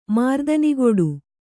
♪ mārdanigoḍu